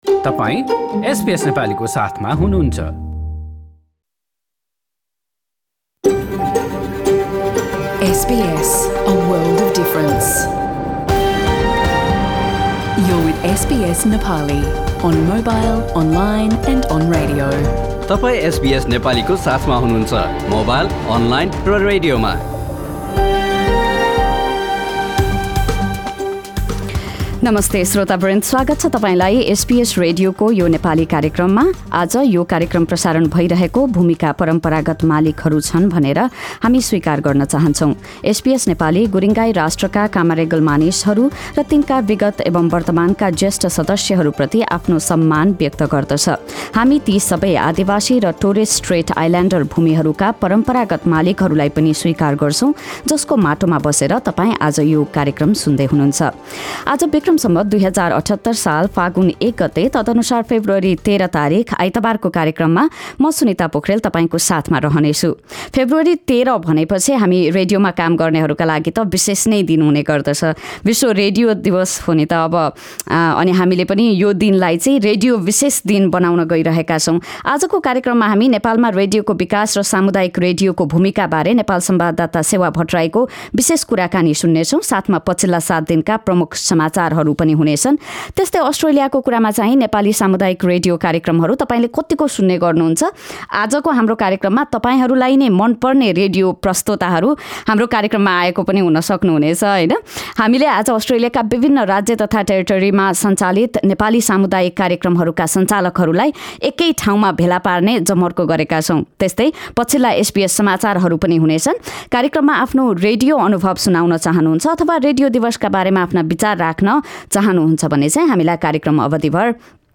In this radio program we talk about: a claim of attempted foreign influence on Australia's next federal election, weekly news from Nepal with a special report on World Radio Day and a live conversation with past and present Nepali community radio presenters based in Australia.